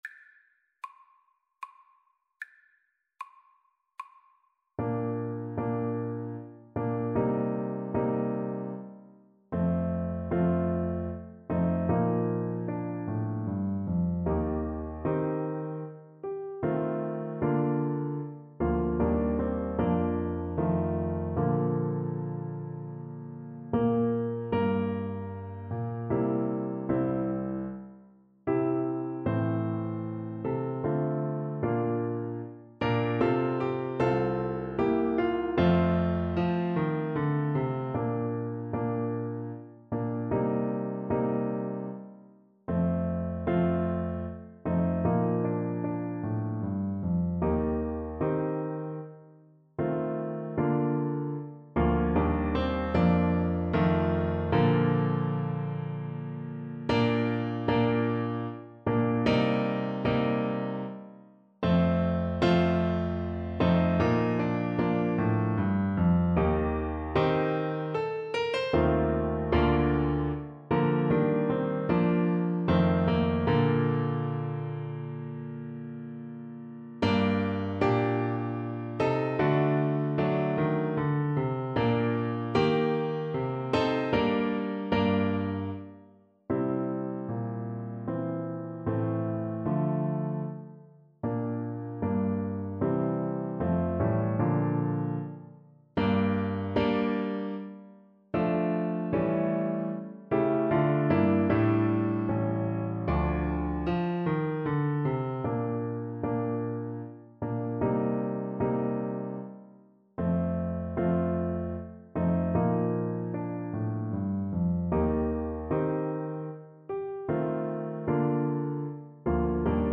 Larghetto = 76
3/4 (View more 3/4 Music)
Bb3-C5
Classical (View more Classical French Horn Music)